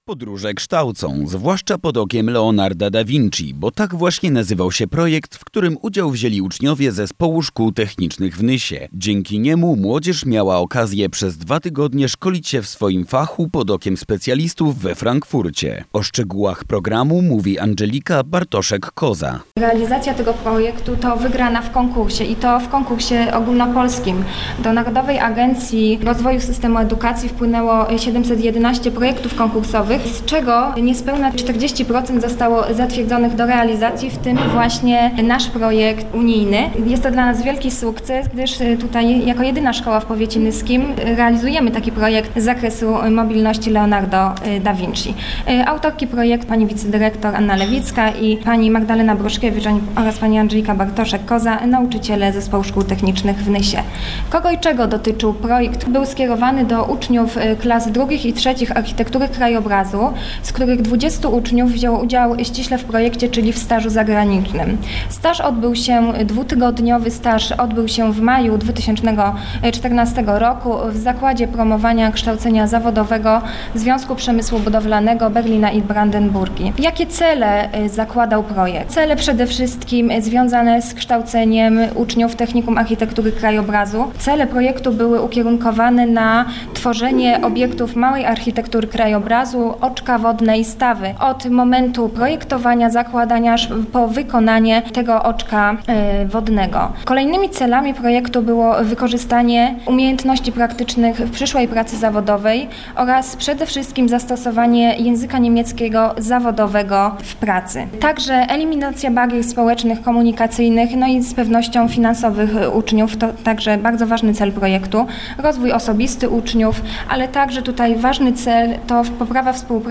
6 listopada 2014 r. – emisja na antenie Radio Nysa FM informacji nt. efektów projektu Leonardo da Vinci oraz odbytej konferencji podsumowującej realizowany projekt
Radio Nysa- wywiad